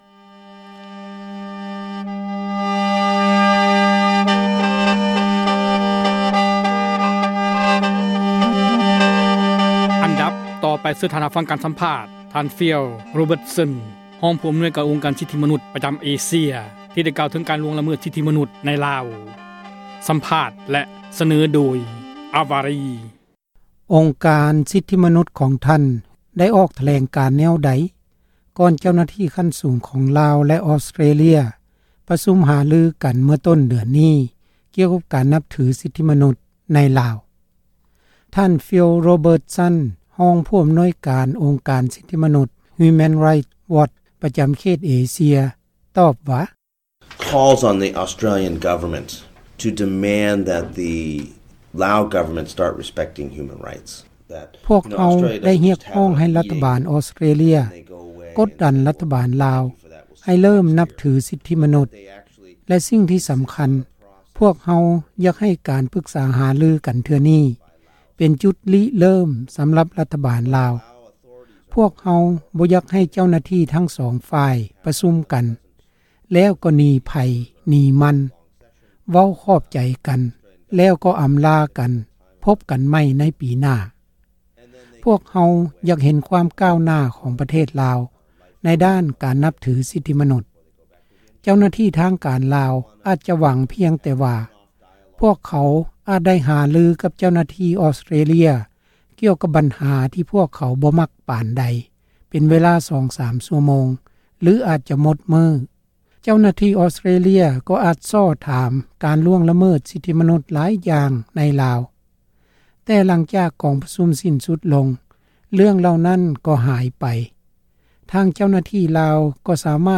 ສຳພາດ
ທີ່ ສໍານັກງານ ໃຫຍ່ ວໍຊິງຕັນ ດີ ຊີ RFA